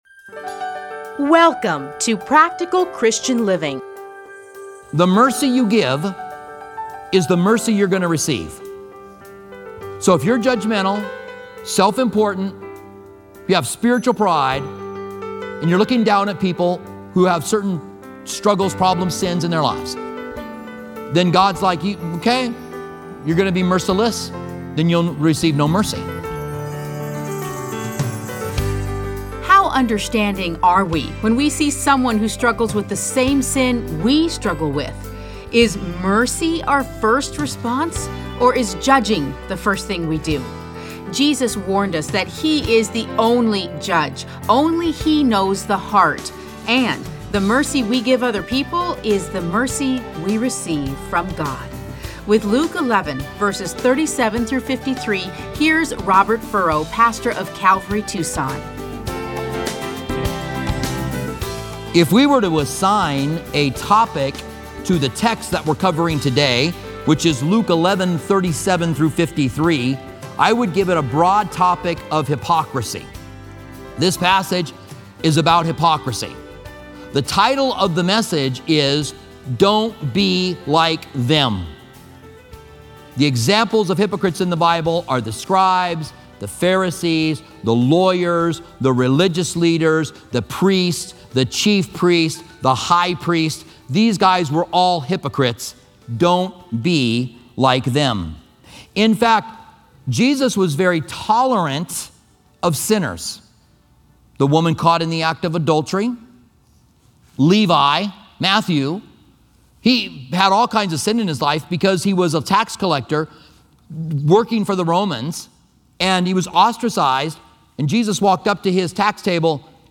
Listen to a teaching from Luke Luke 11:37-53.